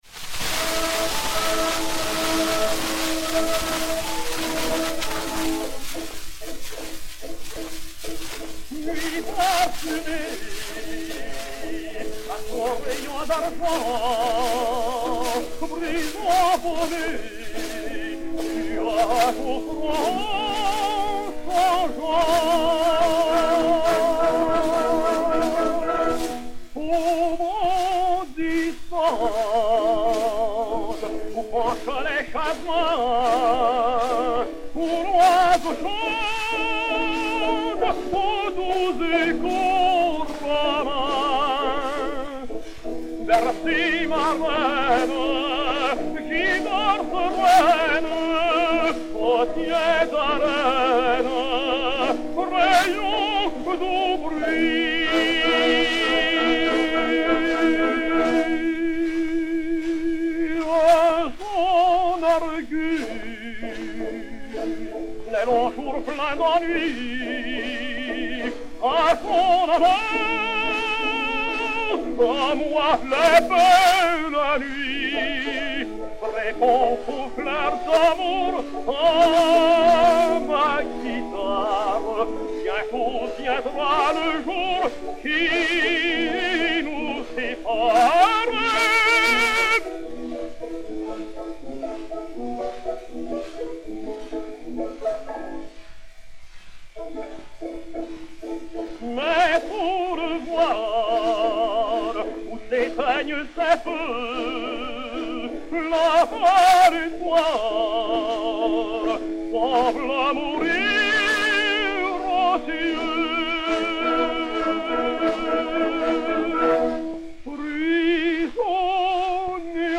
Léon Campagnola (Fernand) et Orchestre
Disque Pour Gramophone 4-32249, mat. 16608u, réédité sur U 4, enr. à Paris le 13 juillet 1911